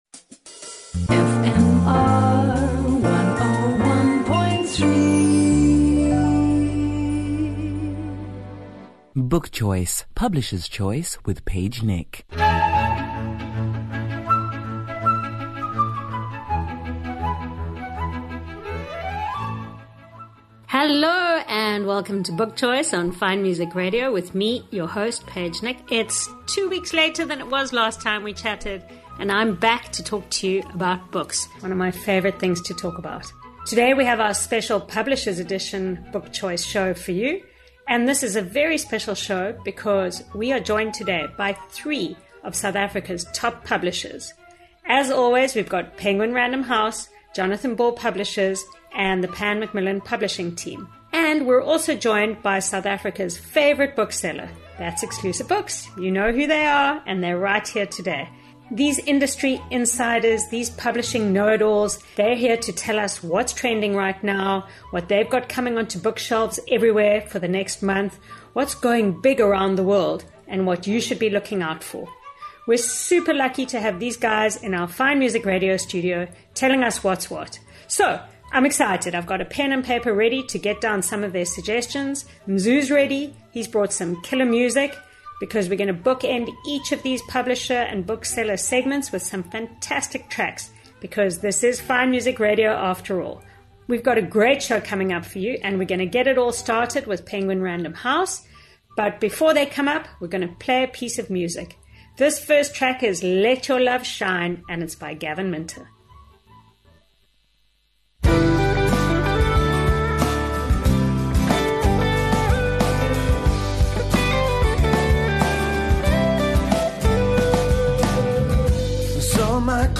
Cape Town’s top book reviewers will entertain and inform you as they cheerfully chat about the newest and nicest fiction and non-fiction on current book shelves. You love author interviews?